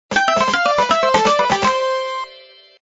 levelwin.mp3